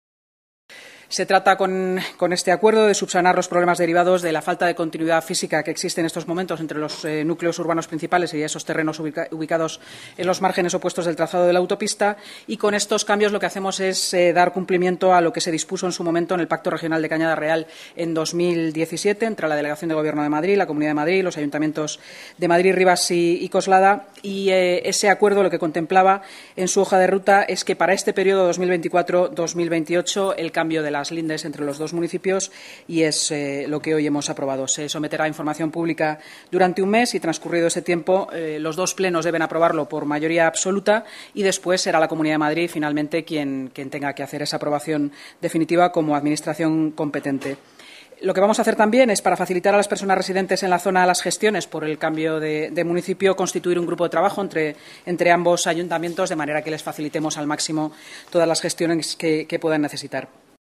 Nueva ventana:Intervención de la vicealcaldesa de Madrid, Inma Sanz, durante la rueda de prensa posterior a la Junta de Gobierno